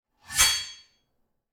SwordSoundPack
SWORD_04.wav